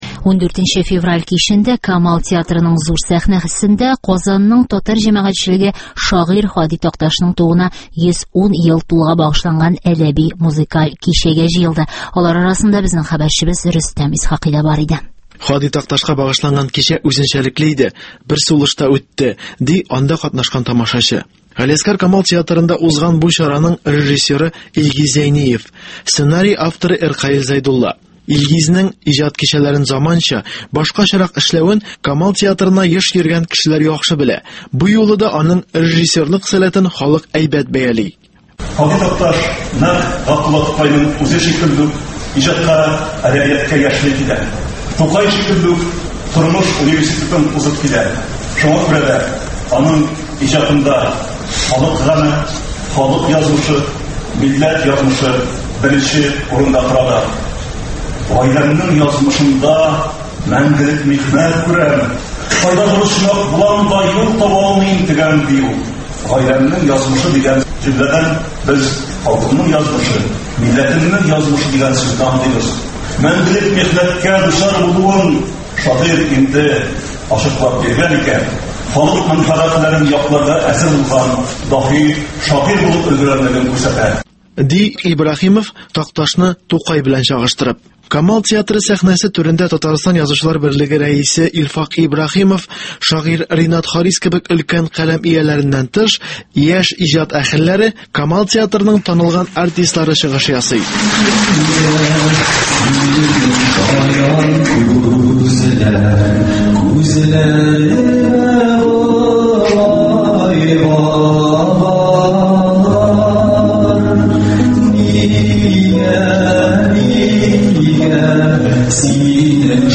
Һади Такташның тууына 110 ел тулуга багышланган кичәдән репортаж